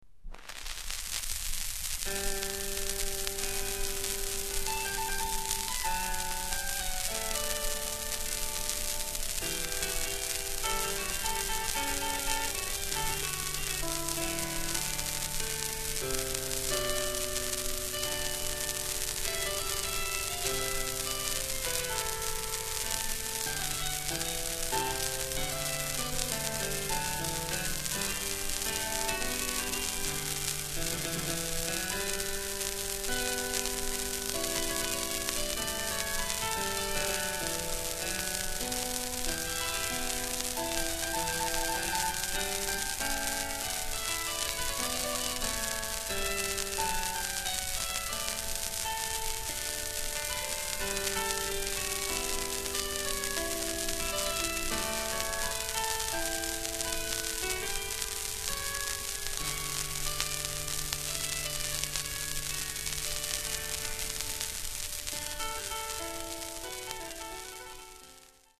ハープシコード
(1933年パリ録音)